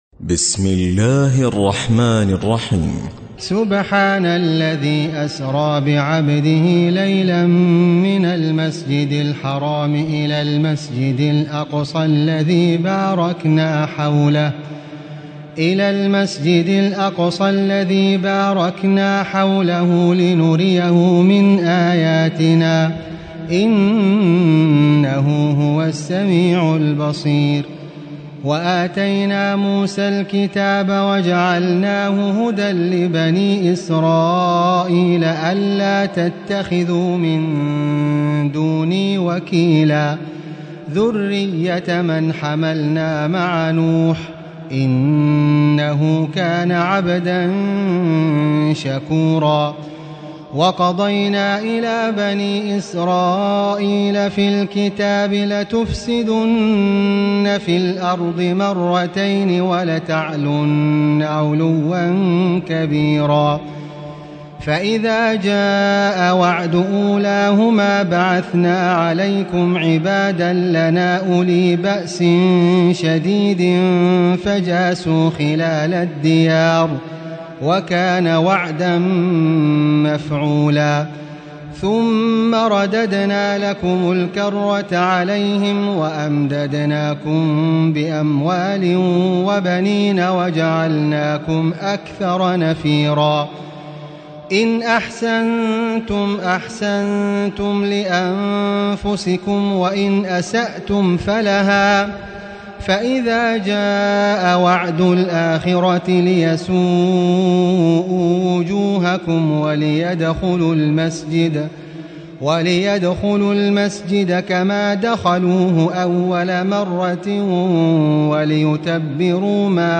تراويح الليلة الرابعة عشر رمضان 1437هـ من سورة الإسراء (1-100) Taraweeh 14 st night Ramadan 1437H from Surah Al-Israa > تراويح الحرم المكي عام 1437 🕋 > التراويح - تلاوات الحرمين